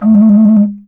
Index of /90_sSampleCDs/Best Service ProSamples vol.52 - World Instruments 2 [AIFF, EXS24, HALion, WAV] 1CD/PS-52 AIFF WORLD INSTR 2/WOODWIND AND BRASS/PS MOCENO BASSFLUTE LICKS